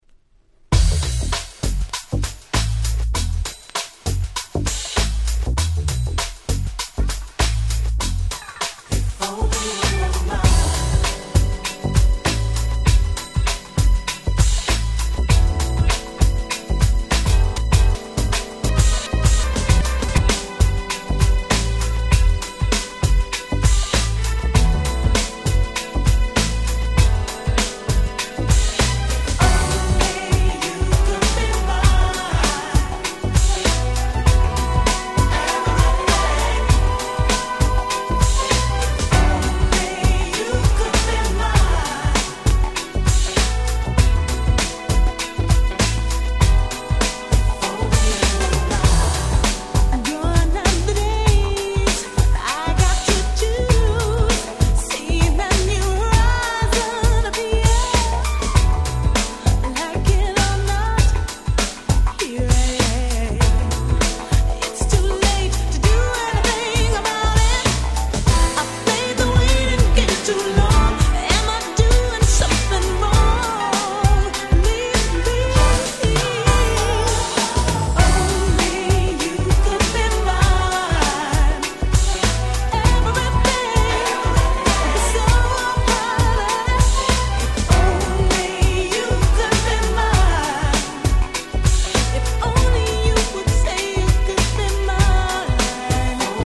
UK Original Press.
95' Nice UK R&B !!